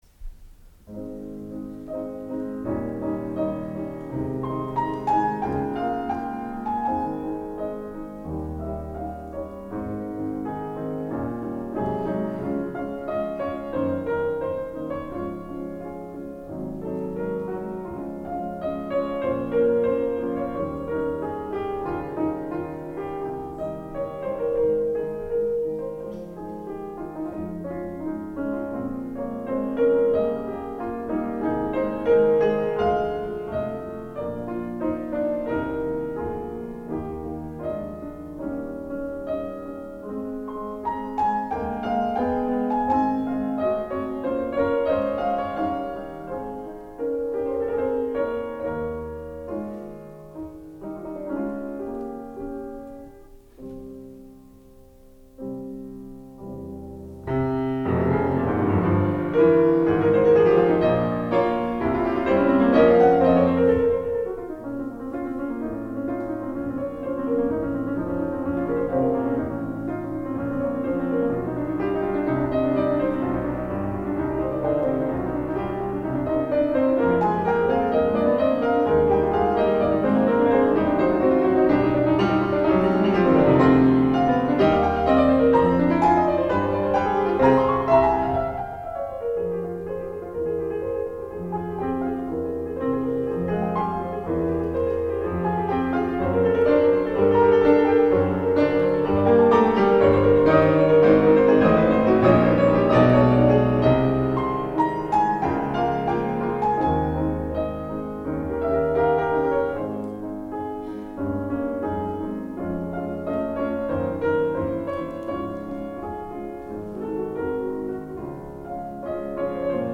Listen to historic chamber music recordings online as heard at Vermont's Marlboro Music Festival, classical music's most coveted retreat since 1951.
Six Etudes en forme de Canon, Op. 56 [2-piano arrangement by C. Debussy (1891)]